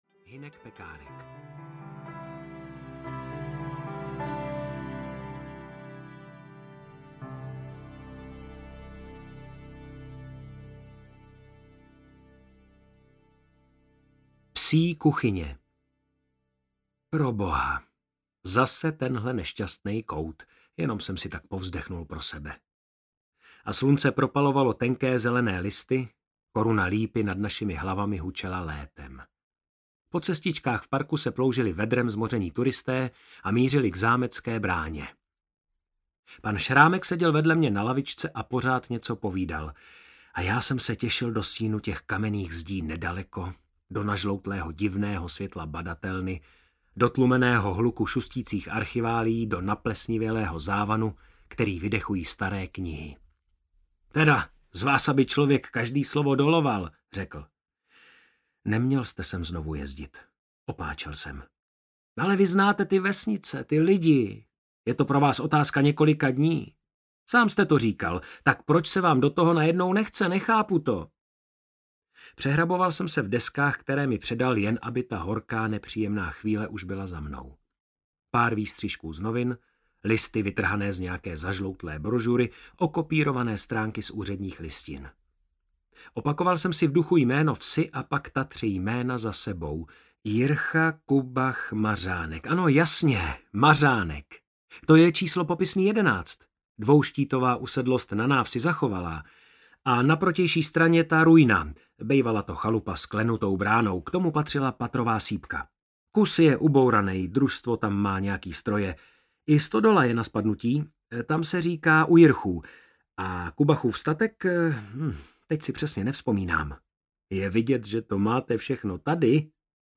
Selský baroko audiokniha
Ukázka z knihy
• InterpretMartin Písařík